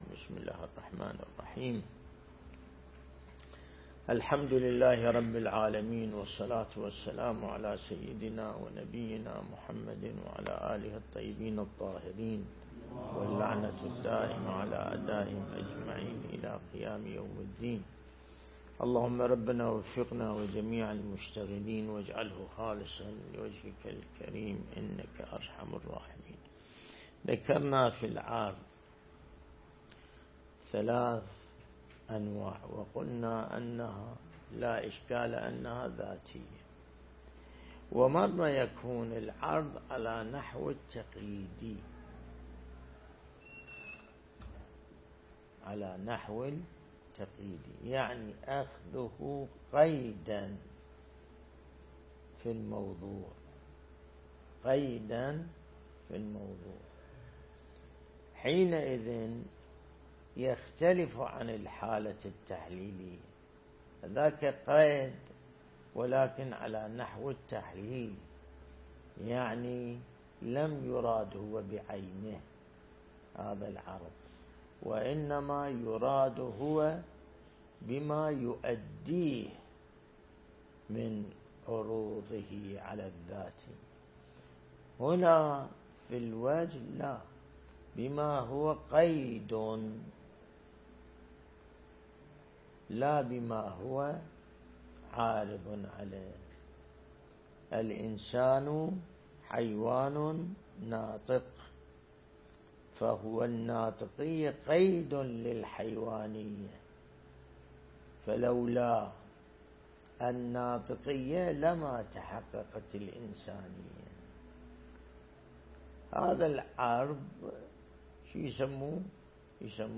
درس البحث الخارج الأصول (48) | الموقع الرسمي لمكتب سماحة آية الله السيد ياسين الموسوي «دام ظله»
النجف الأشرف